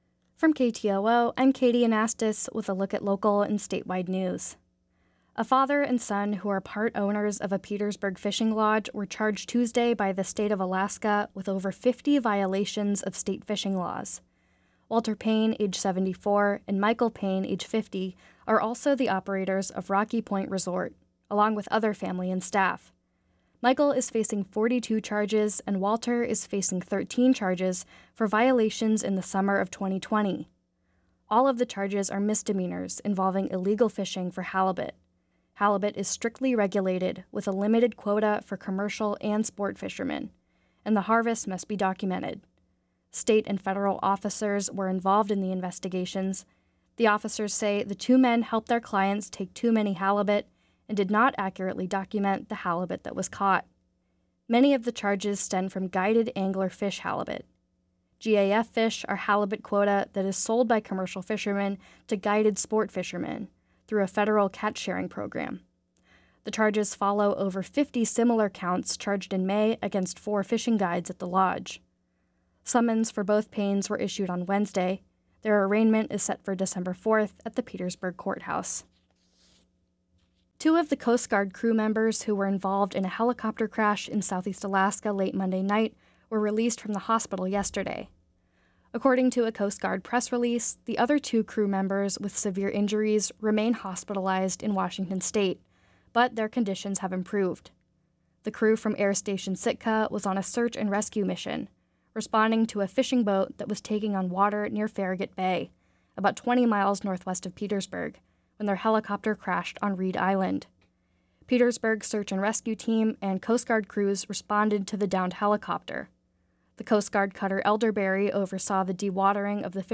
Newscast – Thursday, Nov. 16, 2023